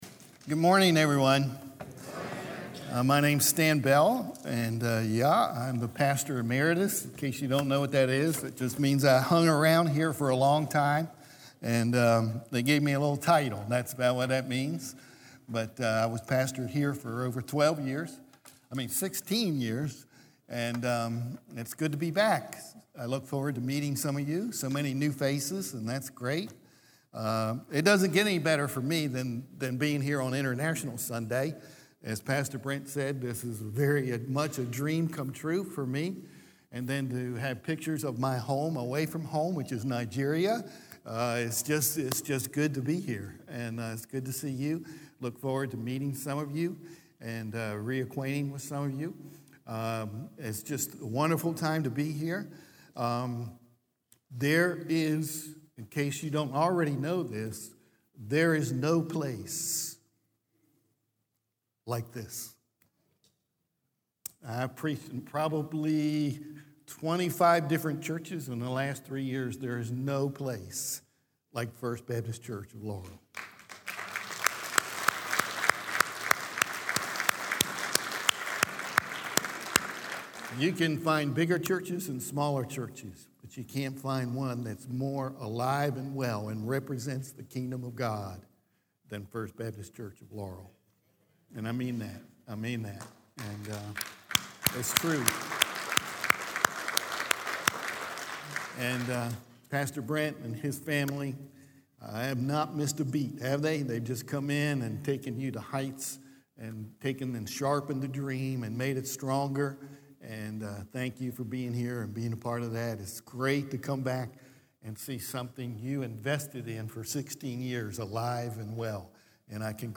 Messages from guest speakers at our weekend services.